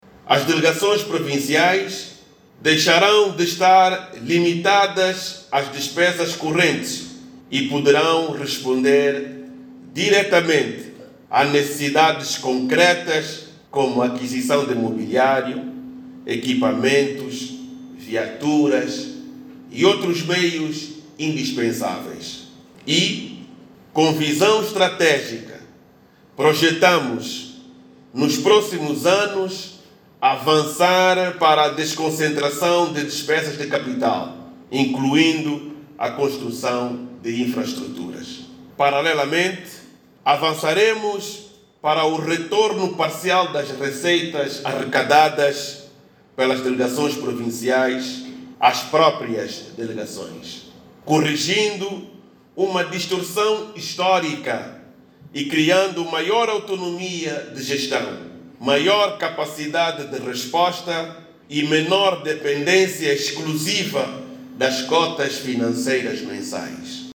A partir de janeiro, as delegações provinciais do Ministério do Interior passarão a ter gestão financeira autónoma, no âmbito das novas políticas financeiras implementadas pelo Governo. O anúncio foi feito hoje, quinta-feira, 18, pelo Ministro do Interior, Manuel Homem, durante a abertura do 5.º Conselho Consultivo da instituição, que está a decorrer em Luanda.
MANUEL-HOMEM-1-13-HRS.mp3